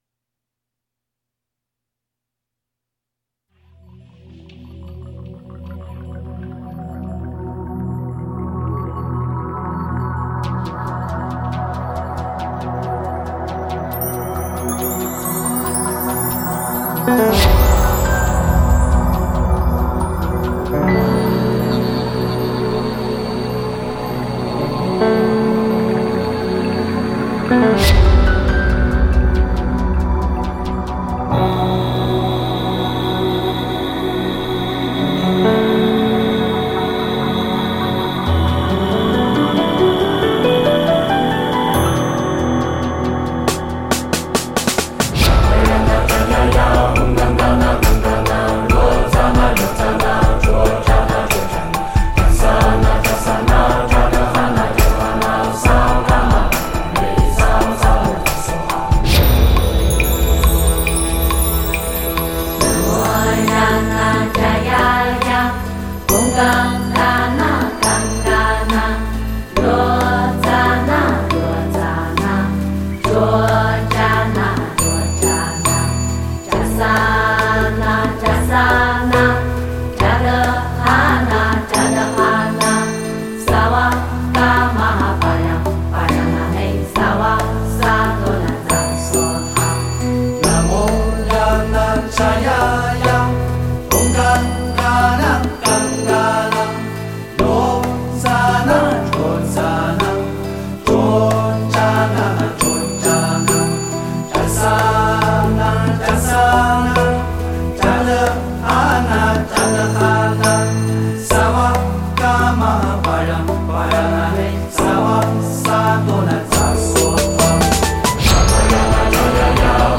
诵经
佛音 诵经 佛教音乐 返回列表 上一篇： 不动佛心咒 下一篇： Drolmai Yang 相关文章 跟着佛祖去流浪--洪启 跟着佛祖去流浪--洪启...